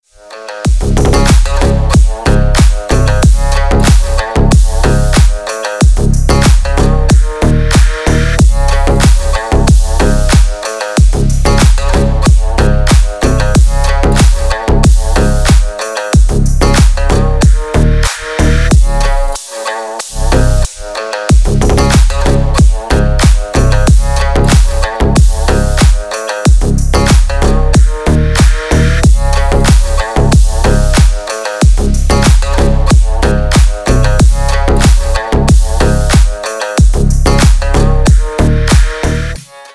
Клубная отбивка для рингтона